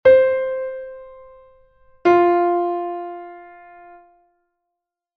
do_fa_quinta.mp3